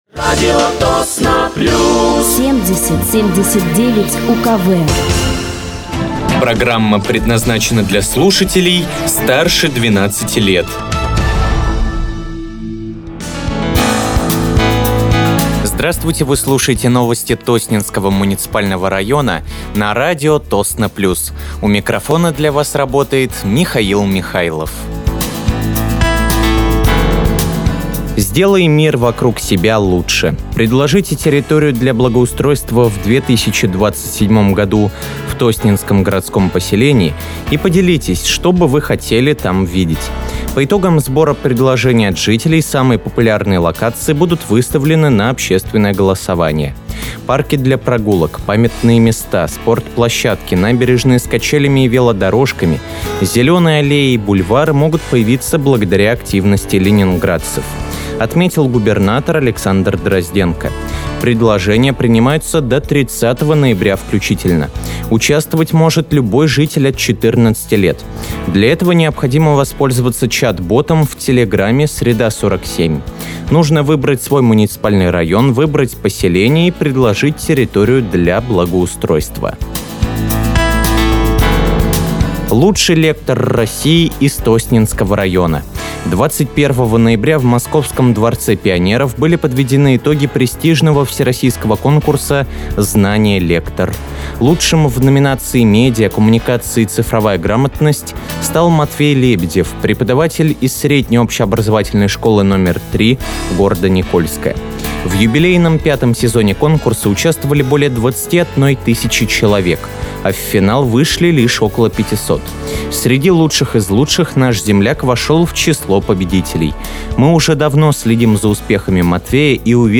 Вы слушаете новости Тосненского муниципального района на радиоканале «Радио Тосно плюс».